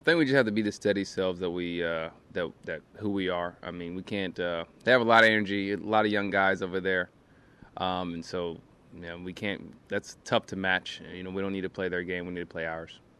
Betts addressed the media on the need to stay true to how the Dodgers play this series.